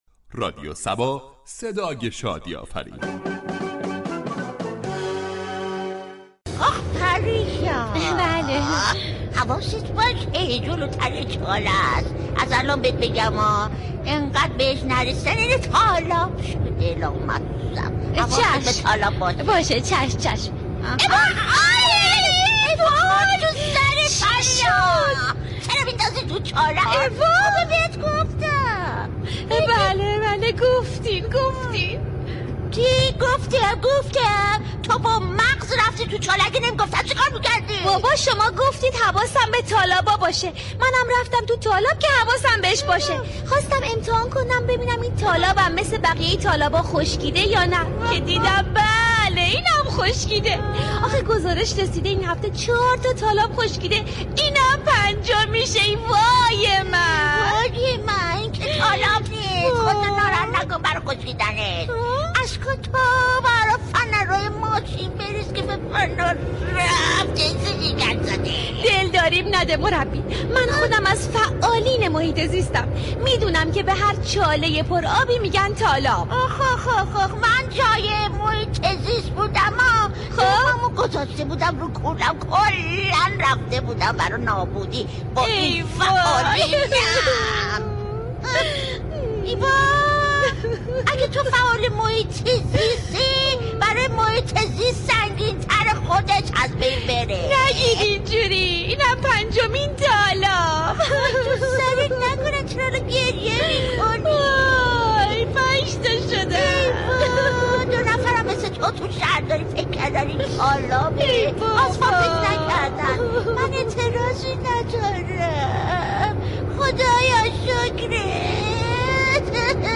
شهر فرنگ در بخش نمایشی با بیان طنز به موضوع " وجود چاله در معابر تهران "پرداخته است ،در ادامه شنونده این بخش باشید.